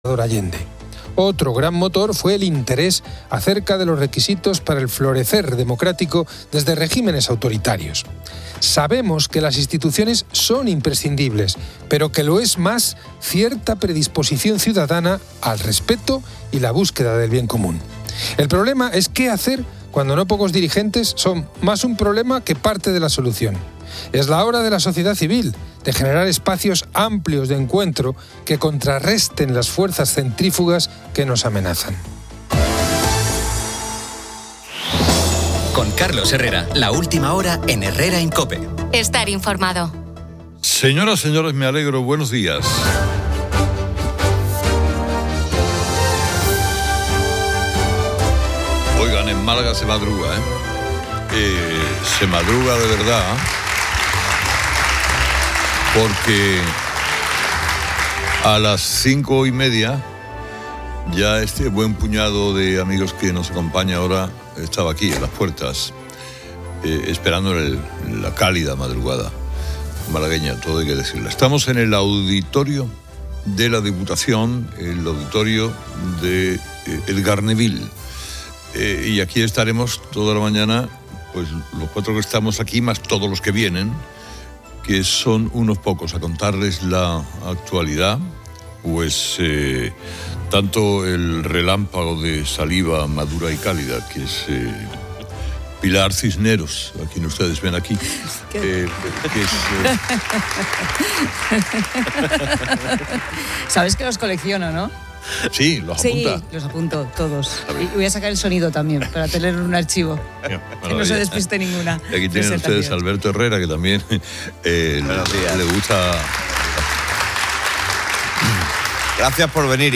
En COPE, Carlos Herrera presenta el programa desde Málaga. Se debate la polémica de la ex-ministra Irene Montero y la ley del "solo sí es sí", así como el escándalo de las pulseras antimaltrato y la postura del gobierno. La cesión de competencias migratorias a Cataluña genera discusión, marcada por la influencia de Aliança Catalana y Puigdemont.